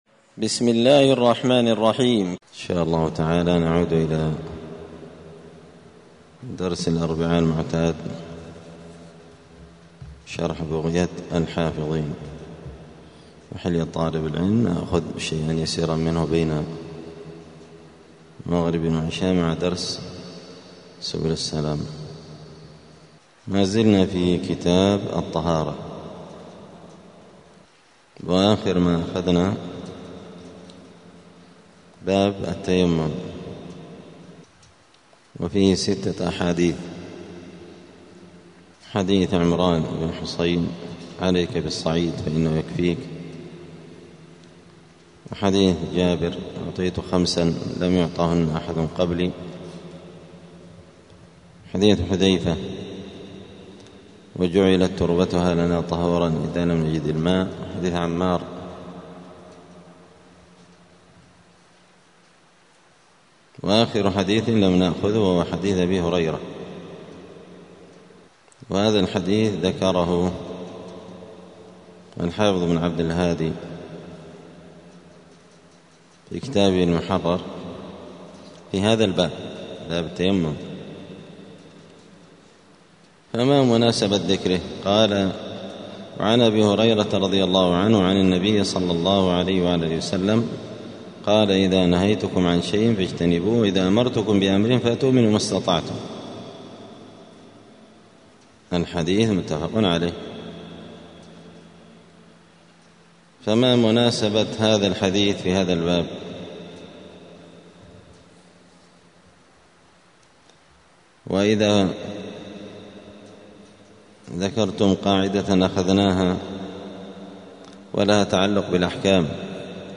دار الحديث السلفية بمسجد الفرقان قشن المهرة اليمن
*الدرس الثالث والتسعون [93] {تكملة باب صفة التيمم وبداية باب الحيض تعريف الحيض وأنواعه}*